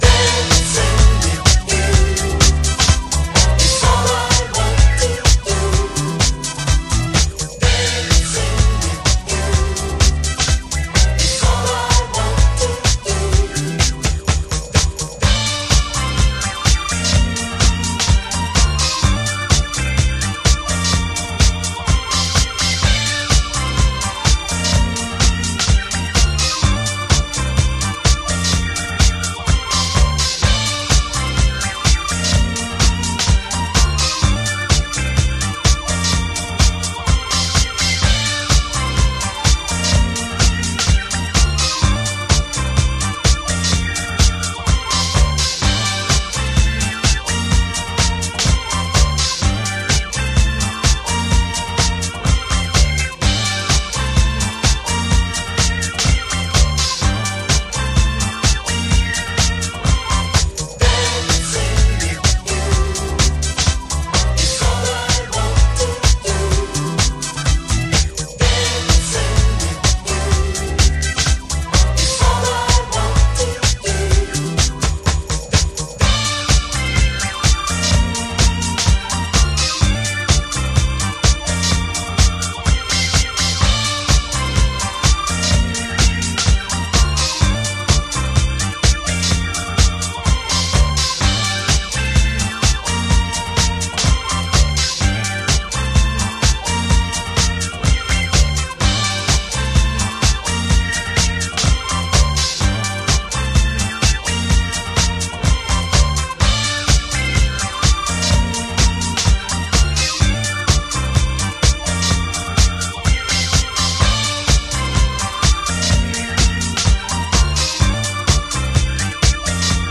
タフに華麗に。